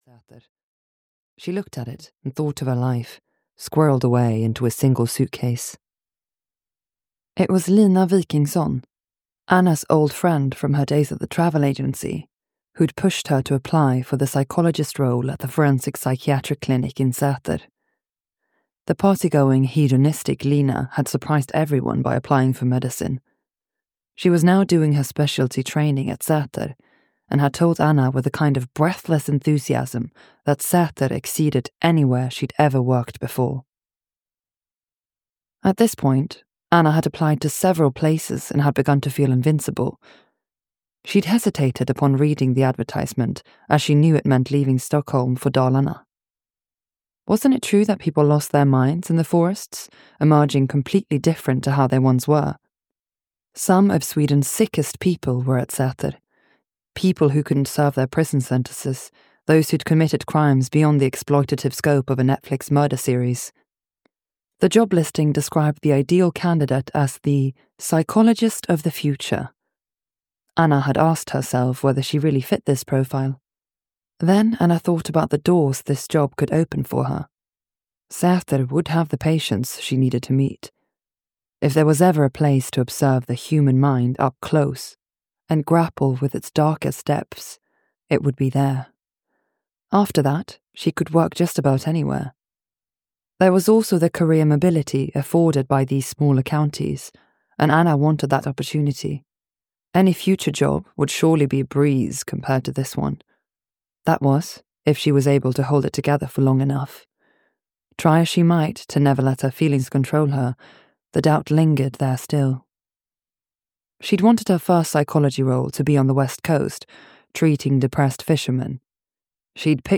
Room 55 (EN) audiokniha
Ukázka z knihy